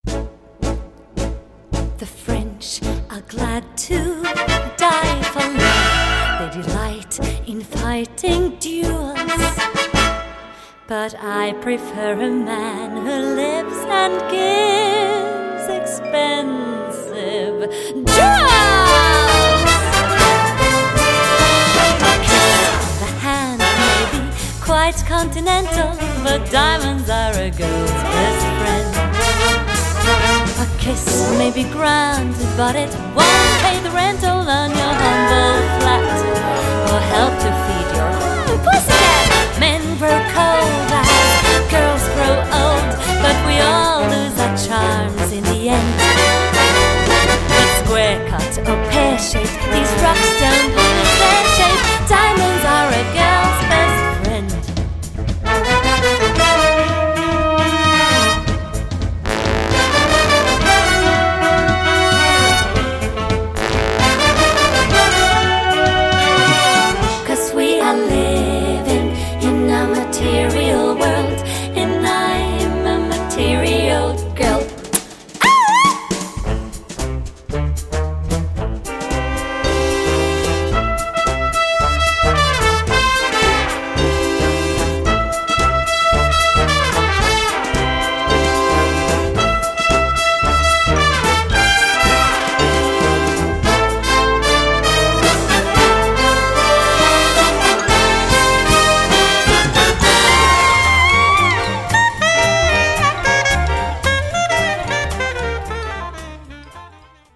Gattung: für Solo Gesang und Blasorchester
Besetzung: Blasorchester